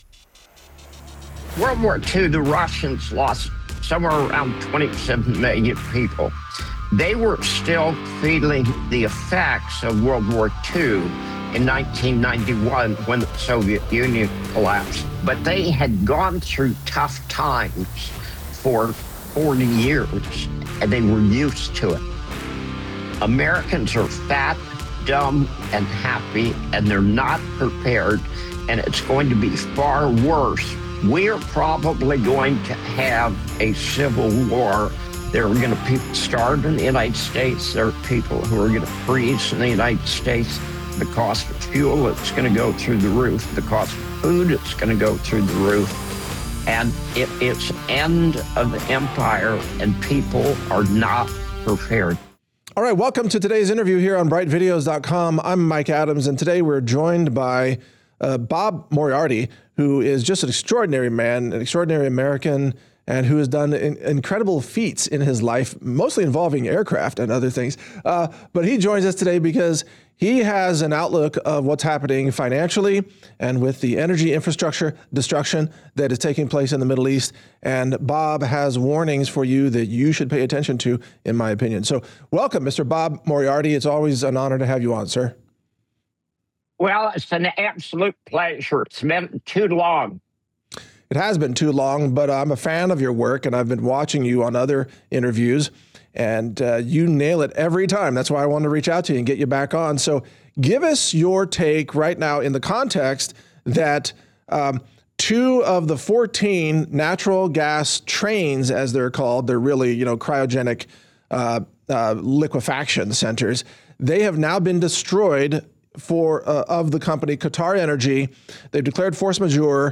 Interview: Why This War Could Collapse the Global System (Part 1)